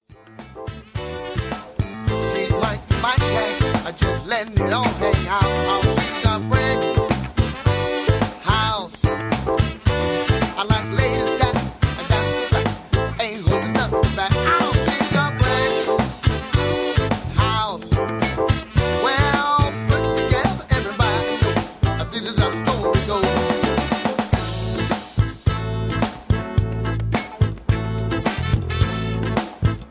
Key-Am) Karaoke MP3 Backing Tracks
Just Plain & Simply "GREAT MUSIC" (No Lyrics).